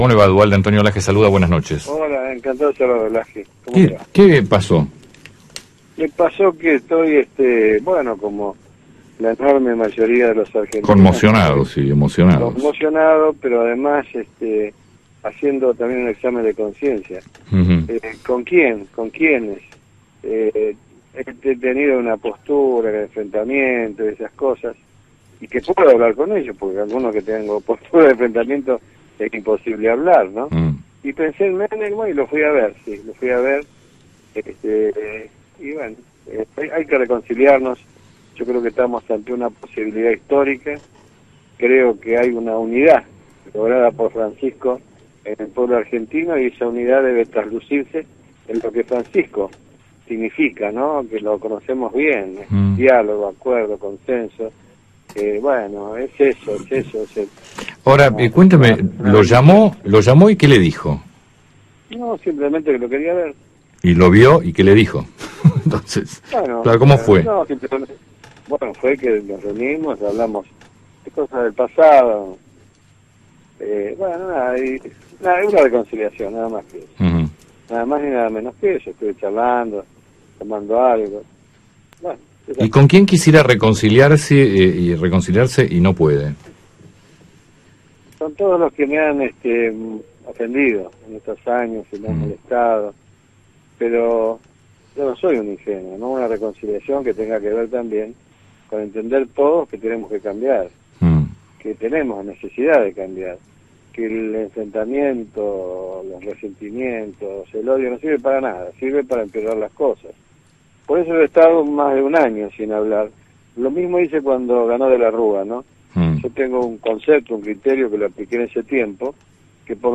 Eduardo Duhalde, ex presidente, por Radio 10